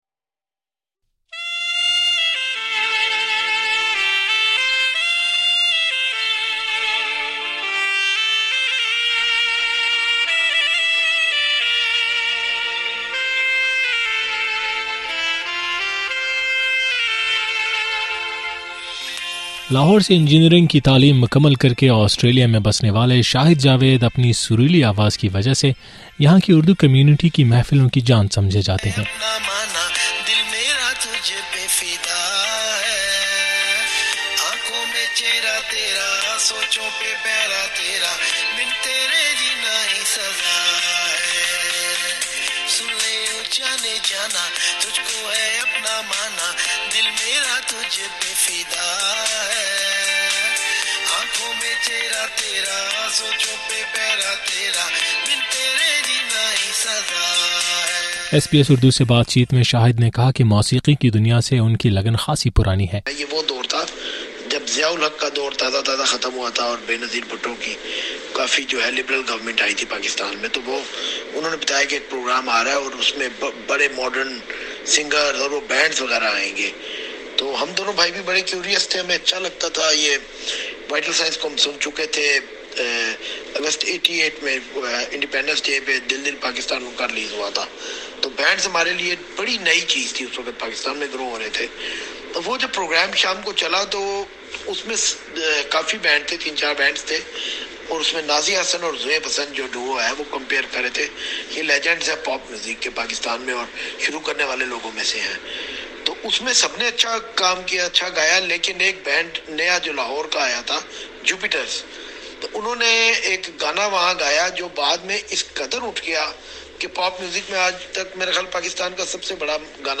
ایس بی ایس اردو سے بات چیت